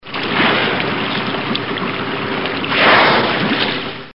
0:00 Group: Tiere ( 628 210 ) Rate this post Download Here!